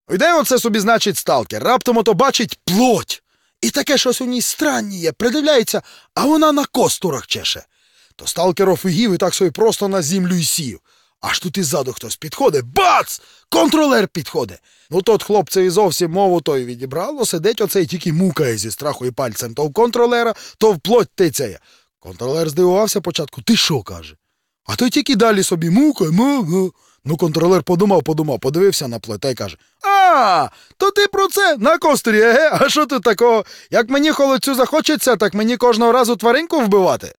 Копав файли Тіні Чорнобиля, відкопав озвучку, і курво: такі опрутненні жарти західняцьким діялектом, що пиздець.